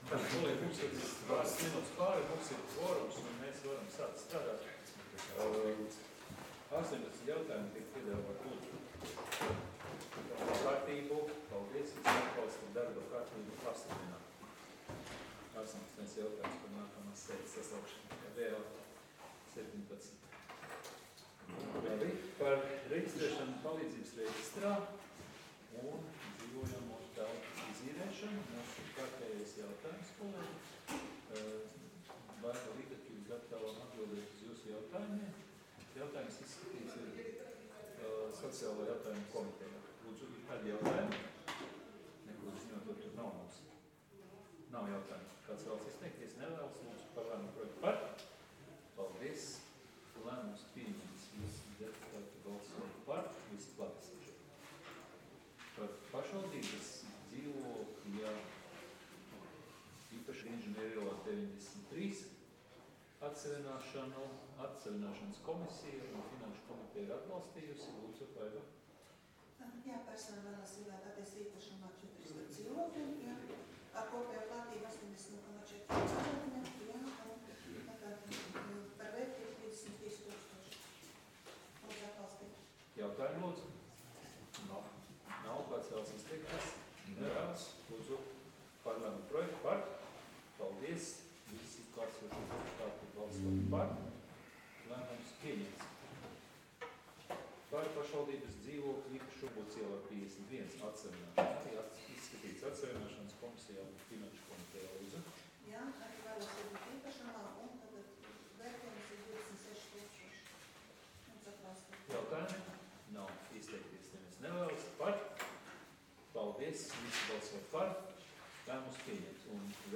Ārkārtas Domes sēdes audioieraksts